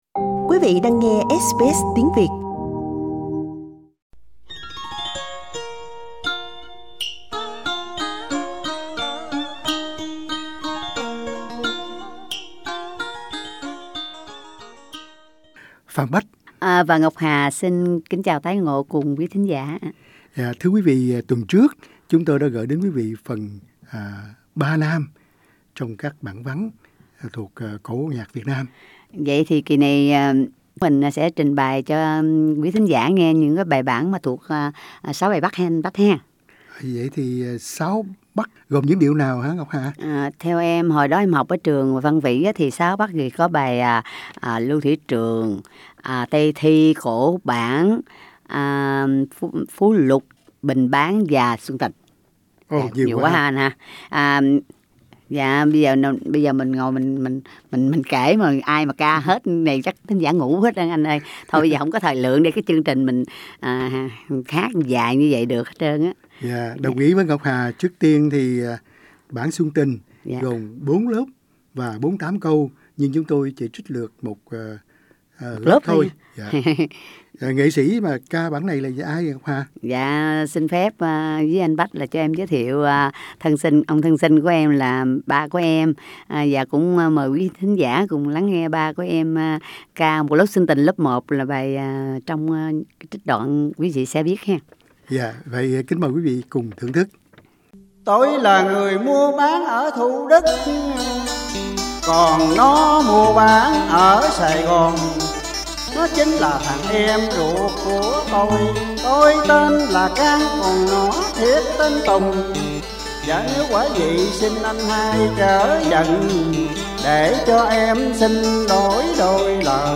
Tiếp tục đem đến cùng quý thính gỉa các bản vắn cải lương, kỳ này là 6 Bắc. Các bản nhạc này có nhiều lớp và nhiều câu, nhưng vì thời phát thanh có hạn, chúng tôi chỉ xin trình bày thường là một lớp mà thôi.
Nhóm ca cổ Ngọc Hà Source: Supplied